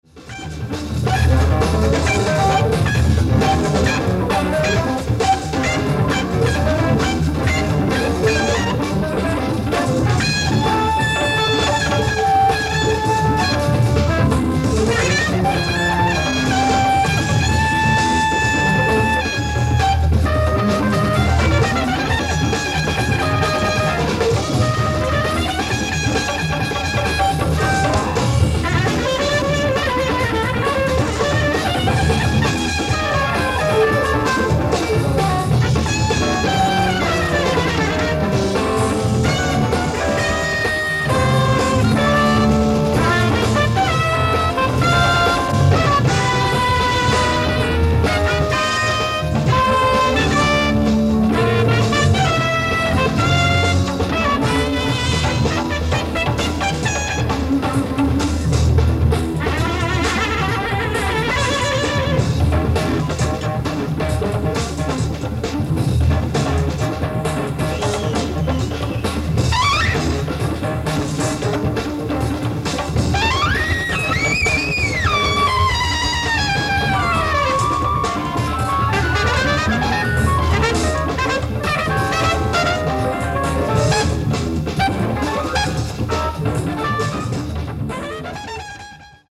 ライブ・アット・ミラノ音楽院ホール、ミラノ、イタリア 10/21/1971
※試聴用に実際より音質を落としています。